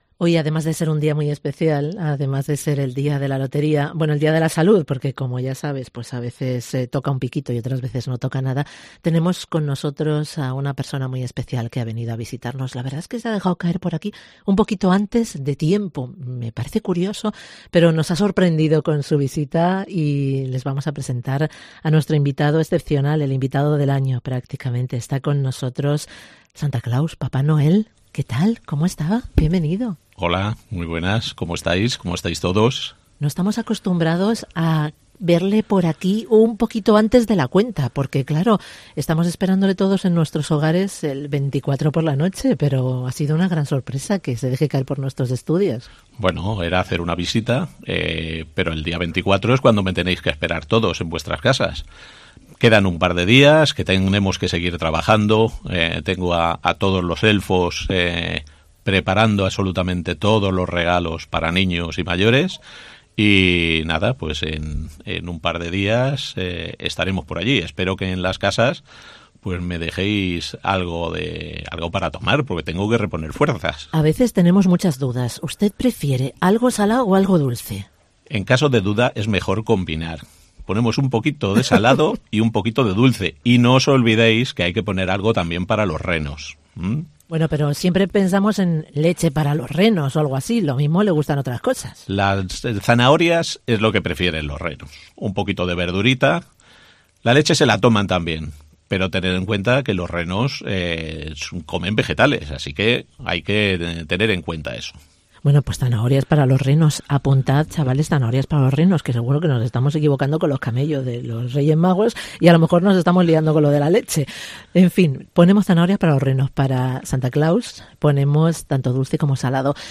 A falta de dos días para la Nochebuena, en COPE de la Costa hemos tenido la suerte de recibir la visita de una persona muy especial, Papa Noel, se ha acercado a nuestros estudios para lanzar un mensaje a los oyentes.